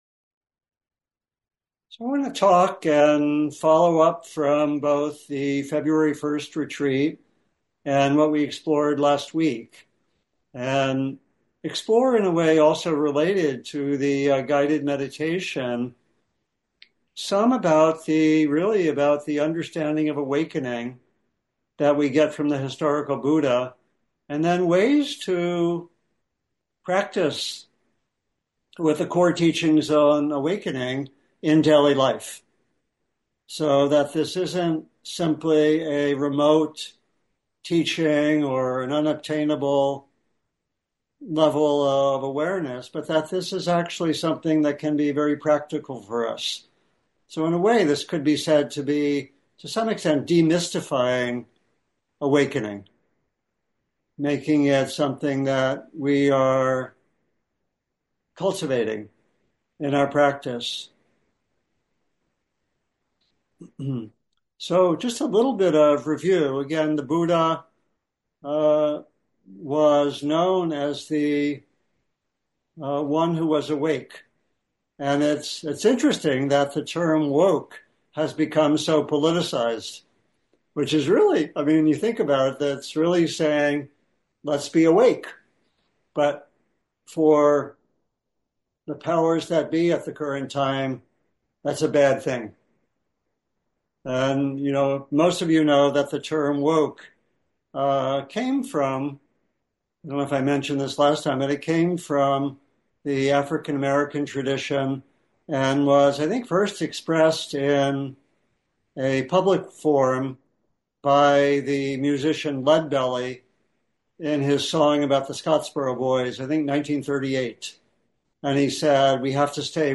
Talk and Discussion: Awakening from the Constructions of Experience in Formal Meditation and Daily Life